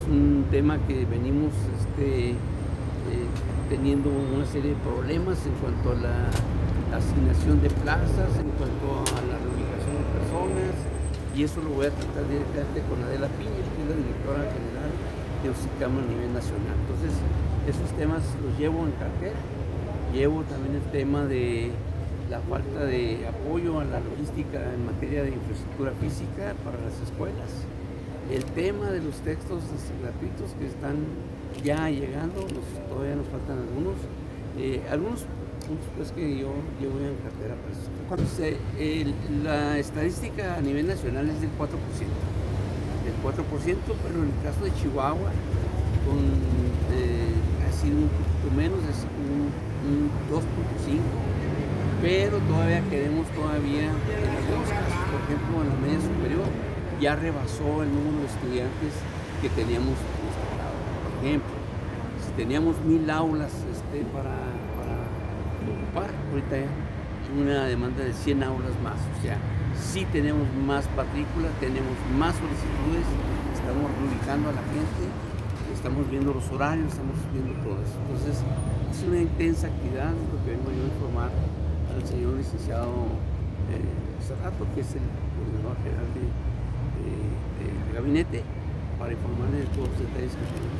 Audio. Secretario de Educación y Deporte, Javier González Mocken.